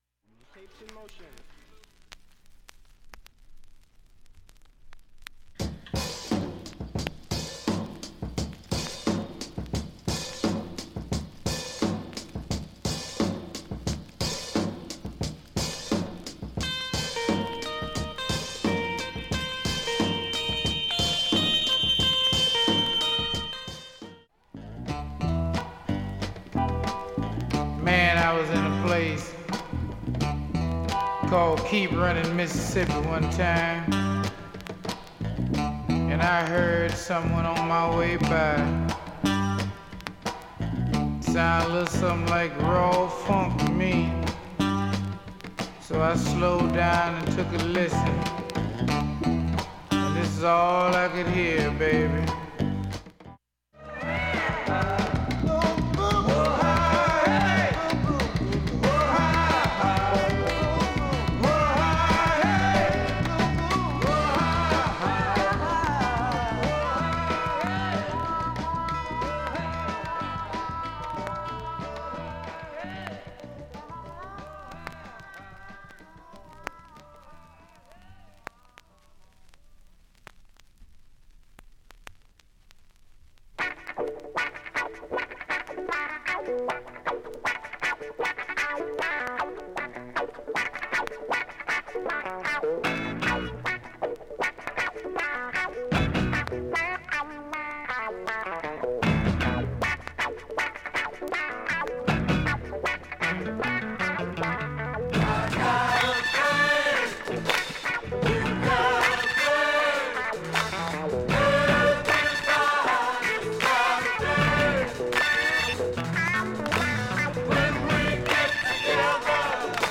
曲間チリも極少なく
音質良好全曲試聴済み。
単発のかすかなプツが６箇所